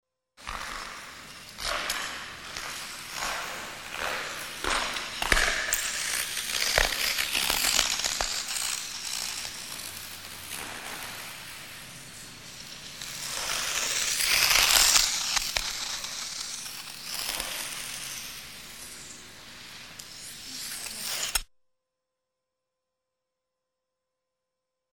Звуки катания на коньках беговых
Катание на льду по кругу. В конце характерный звук лезвия